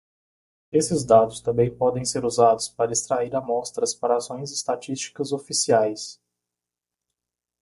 Pronounced as (IPA) /is.tɾaˈi(ʁ)/